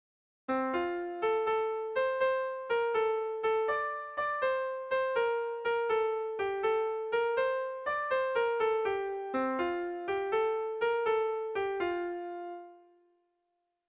Erlijiozkoa
AB